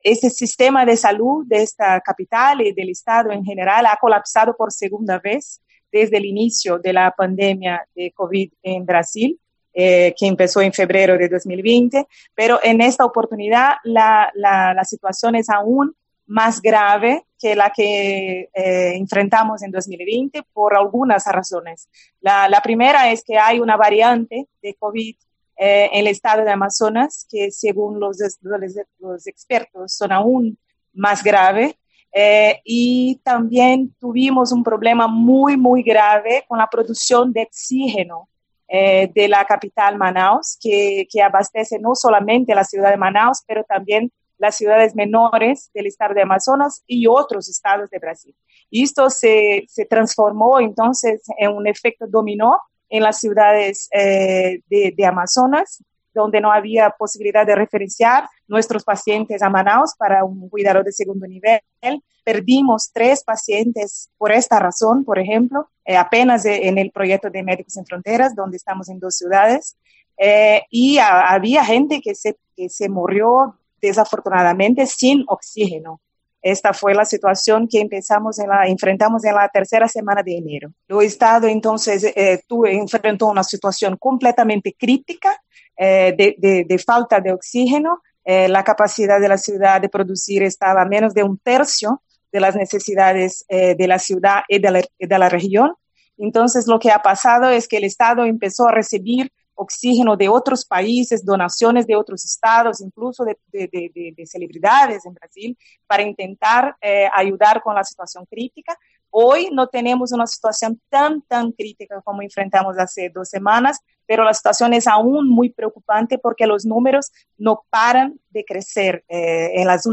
Internacional